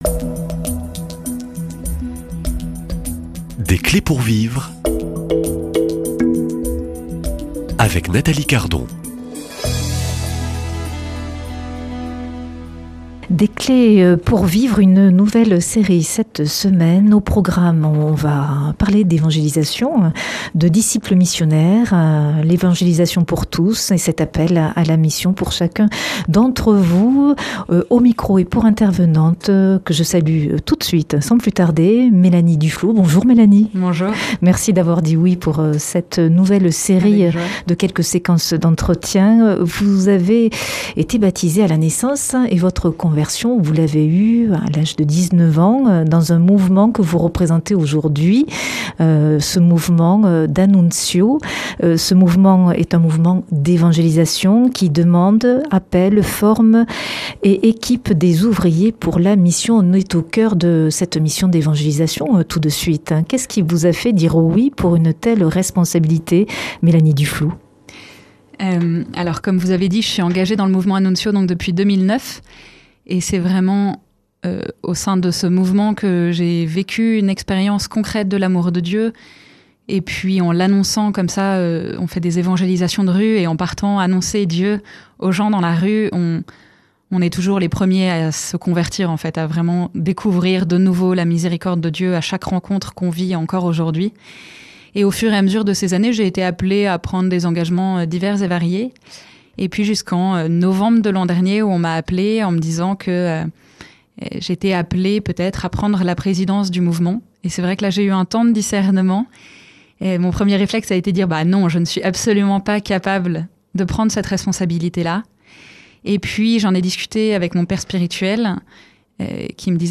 » Une série Des clés pour vivre enregistrée à Lourdes pendant le pélerinage du Rosaire.